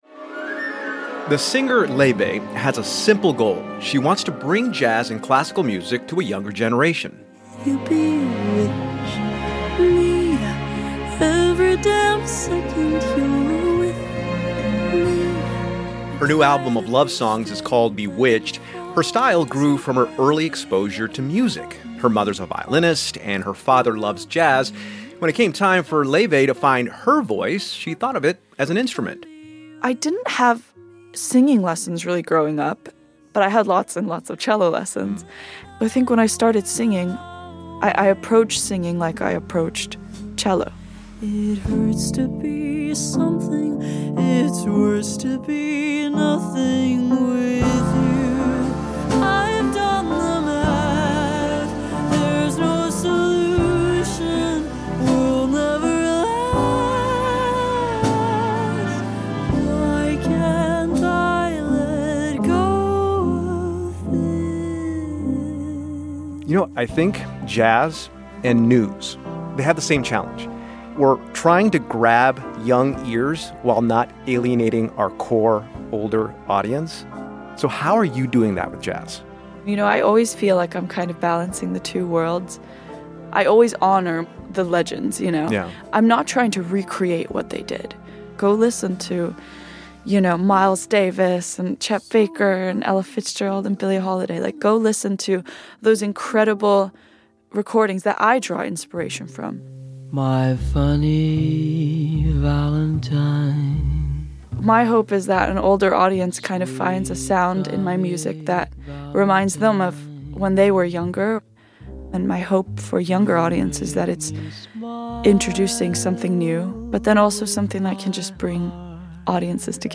NPR's A Martinez speaks with singer Laufey about making jazz more accessible to younger generations.